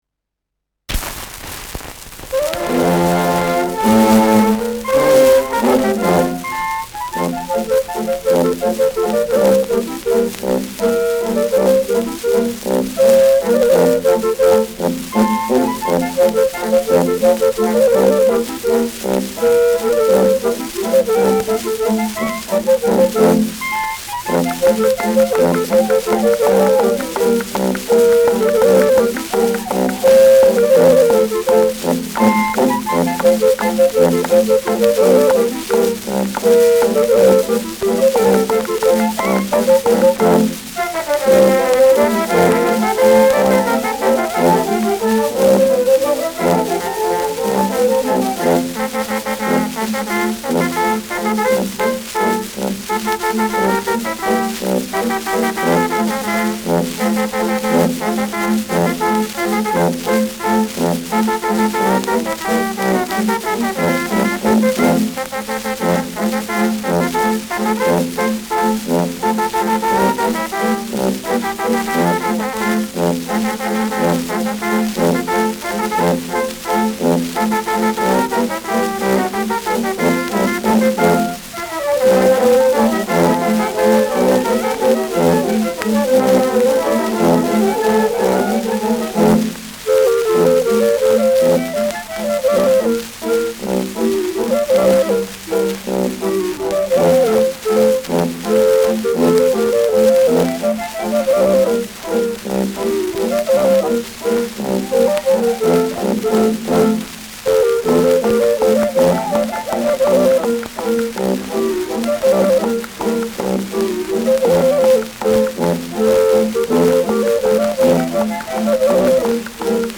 Schellackplatte
präsentes Knistern : leichtes Rauschen : abgespielt : leichtes Leiern
Mit Klopfgeräuschen und Juchzern.
[München] (Aufnahmeort)